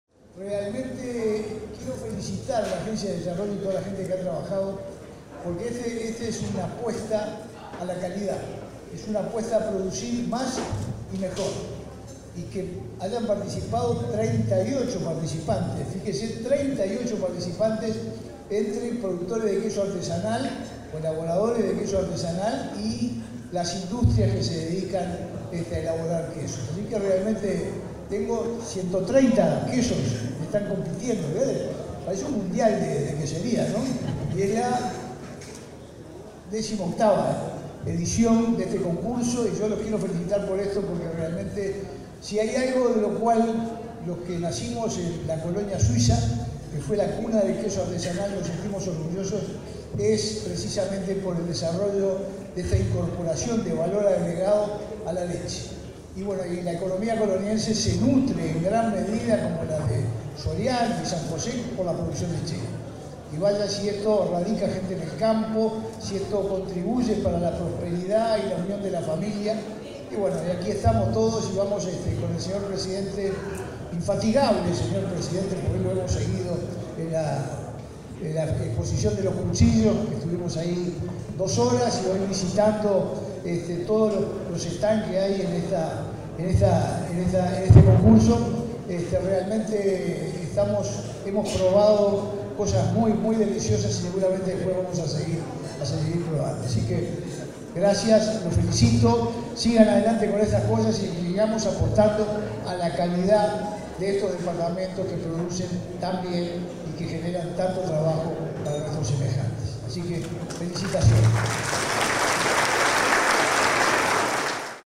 Palabras del intendente de Colonia, Carlos Moreira
El intendente de Colonia, Carlos Moreira, participó de la premiación de la primera Expo feria Quesera de su departamento, a la que asistió el